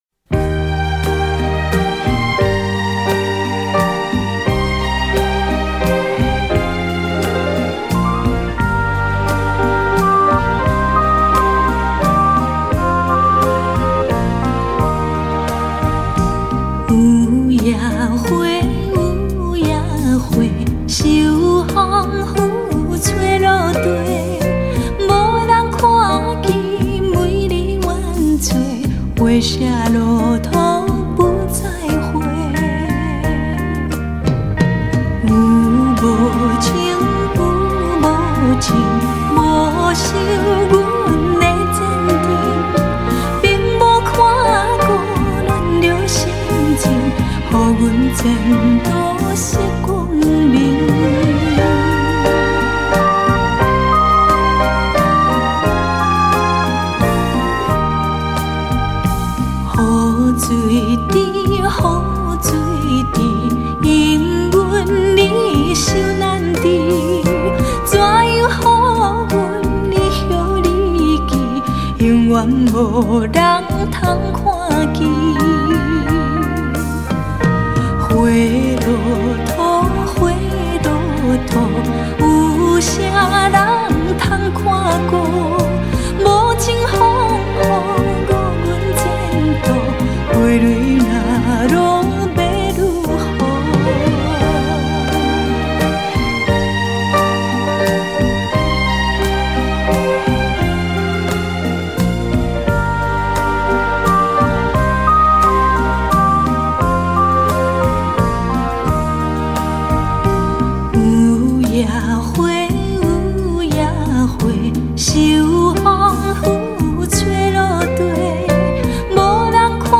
专辑风格：台语流行歌曲